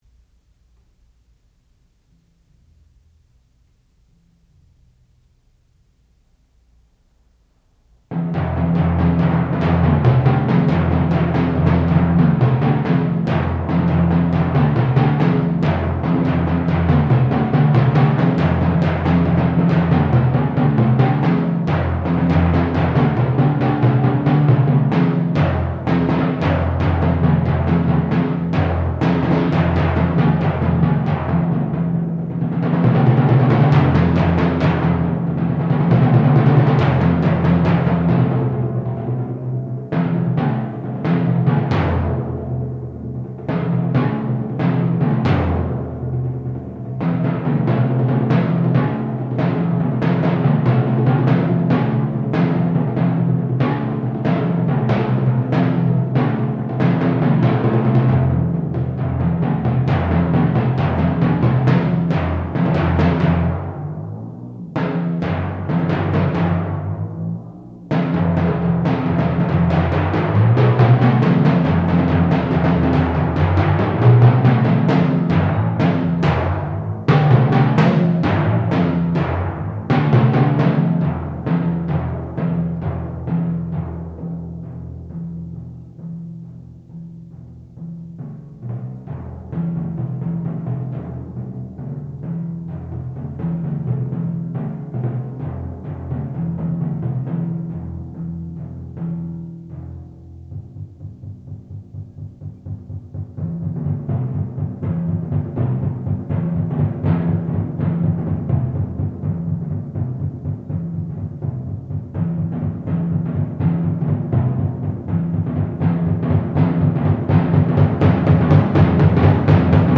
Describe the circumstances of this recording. Master's Recital